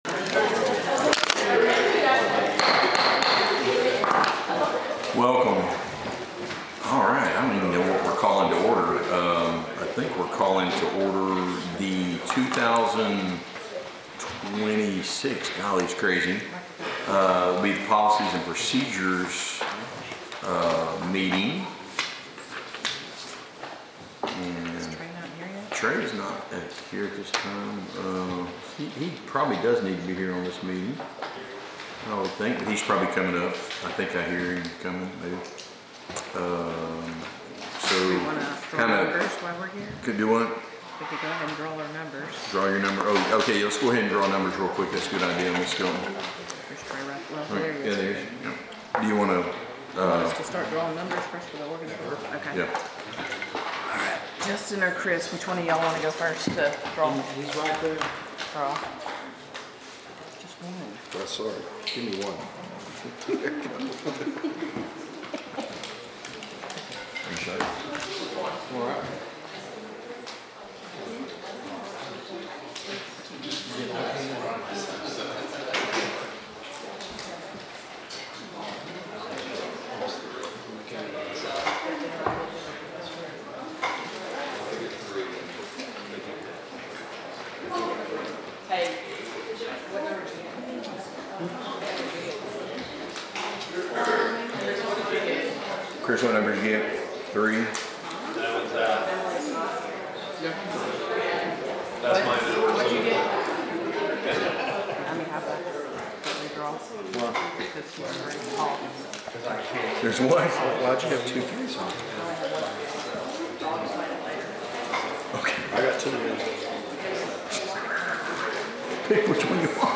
City Council Special Called Meeting January 13, 2026